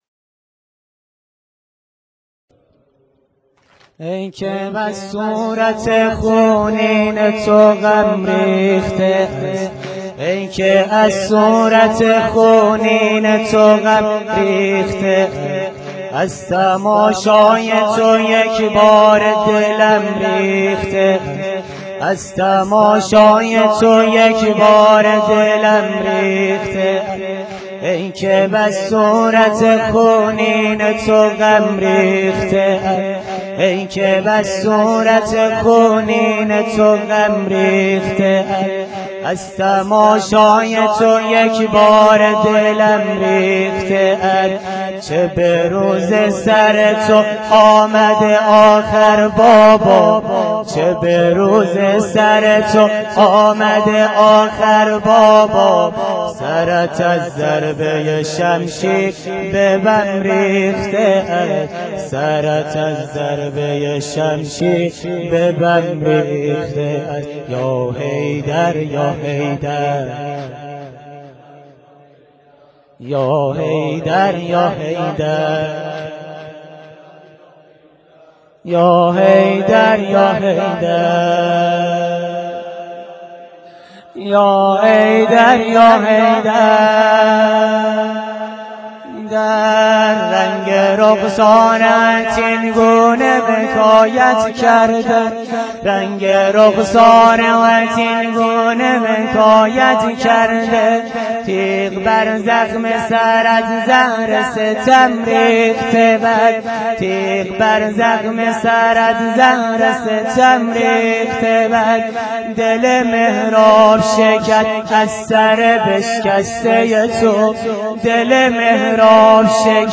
شب 19 رمضان _ مسجد بیت الله.wma